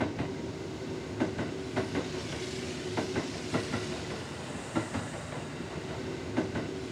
effect__train.wav